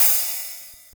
Miss Me OpenHat2.wav